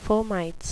fomites (FOM-i-teez) plural noun
Pronunciation: